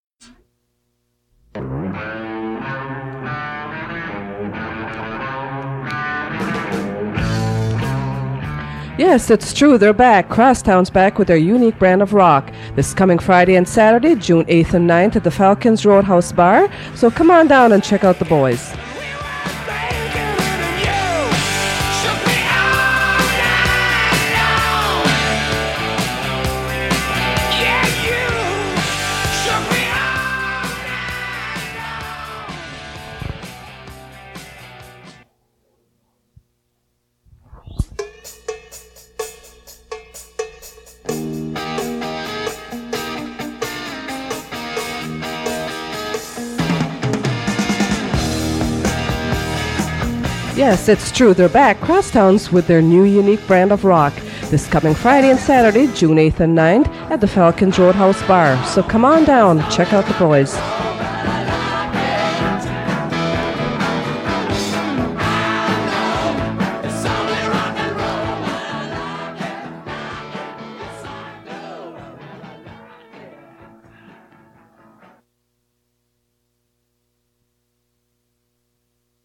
Fait partie de The Crosstown band announcement